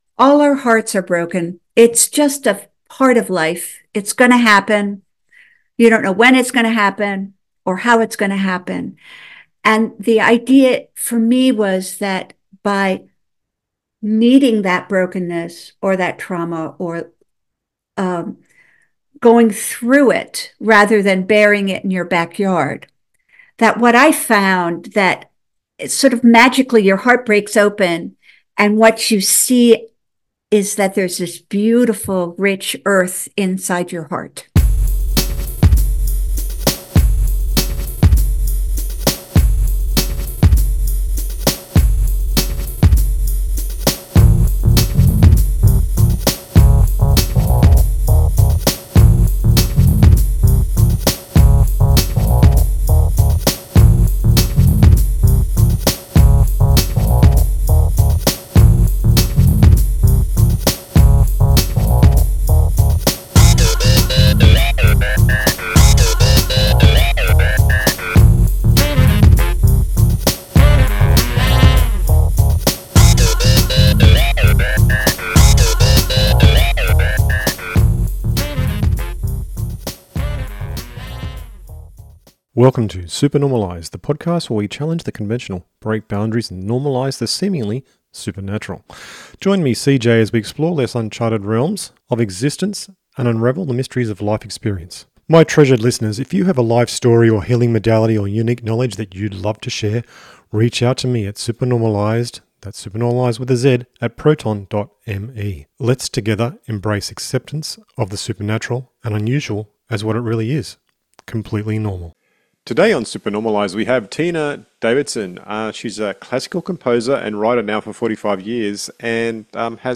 Interview Can Trauma Be Released By Creativity?